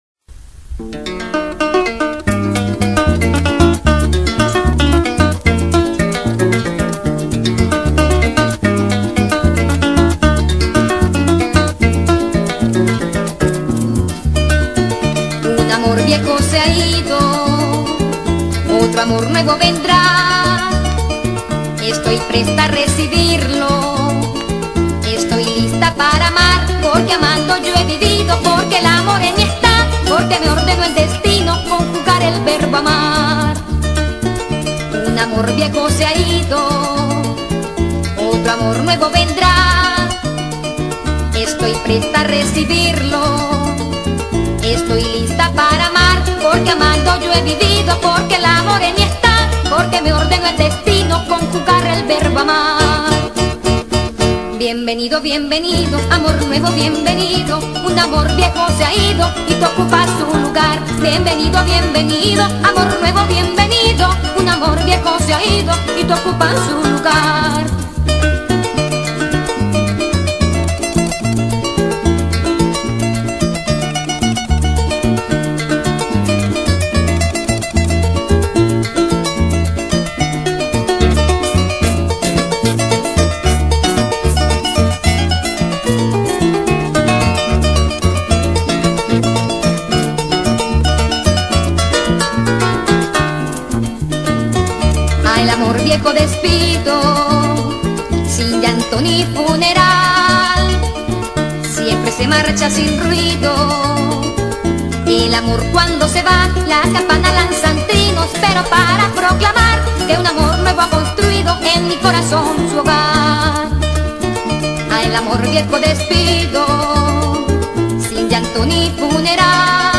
Ritmo: Pasaje.